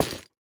Minecraft Version Minecraft Version 1.21.5 Latest Release | Latest Snapshot 1.21.5 / assets / minecraft / sounds / block / nether_wood_hanging_sign / break4.ogg Compare With Compare With Latest Release | Latest Snapshot
break4.ogg